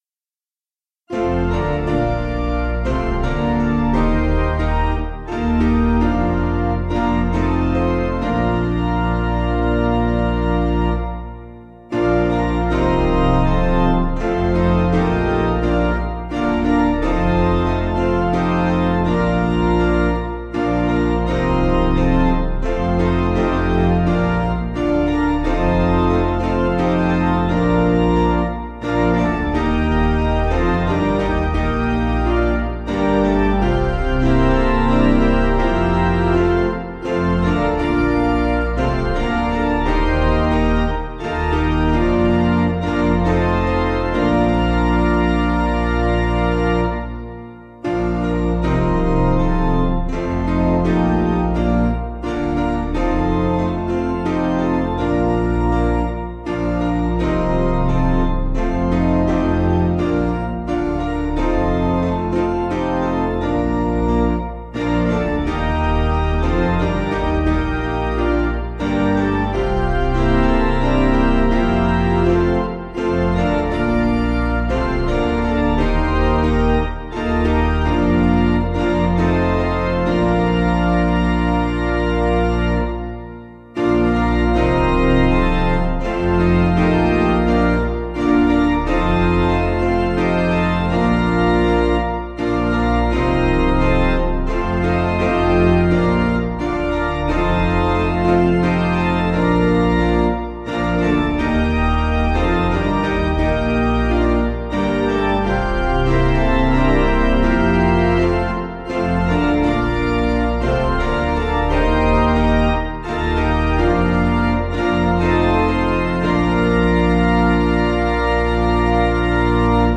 Basic Piano & Organ
(CM)   3/Bb